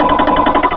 Cri de Charmina dans Pokémon Rubis et Saphir.